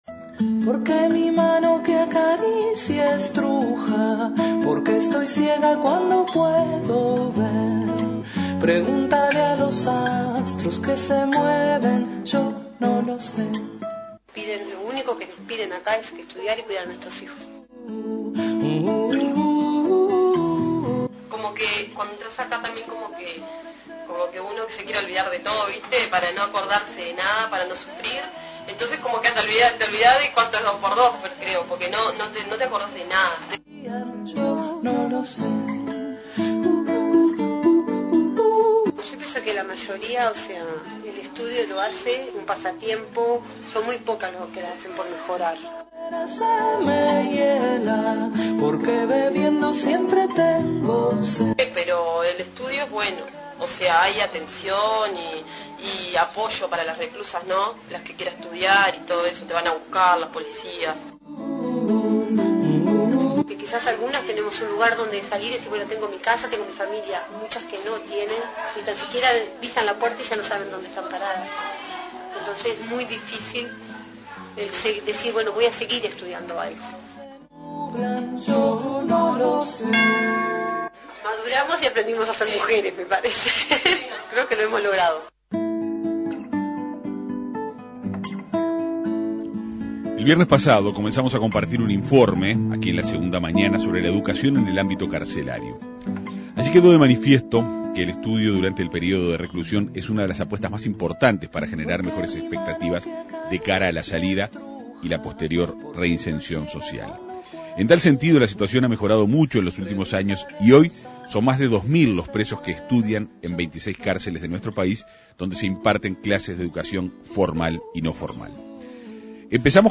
El estudio durante el período de reclusión es la apuesta más importante para revertir este proceso. Escuche el informe de la Segunda Mañana de En Perspectiva.